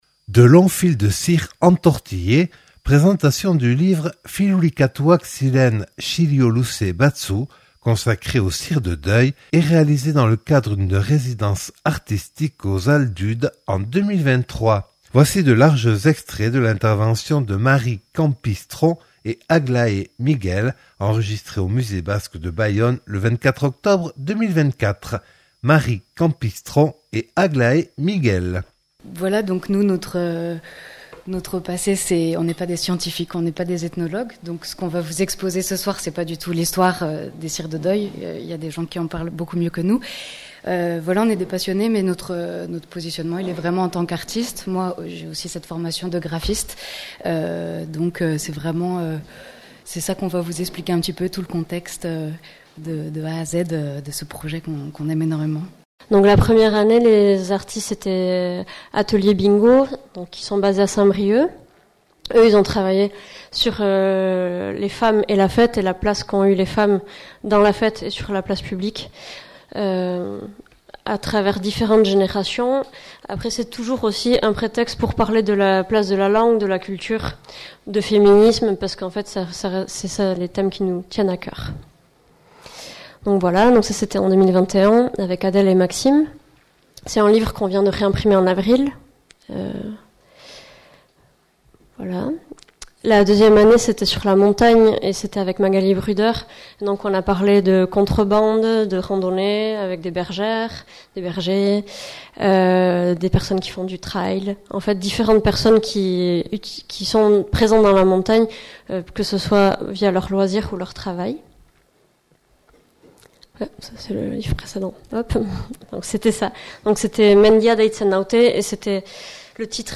(Enregistré au Musée Basque et de l’histoire de Bayonne le 24/10/2024).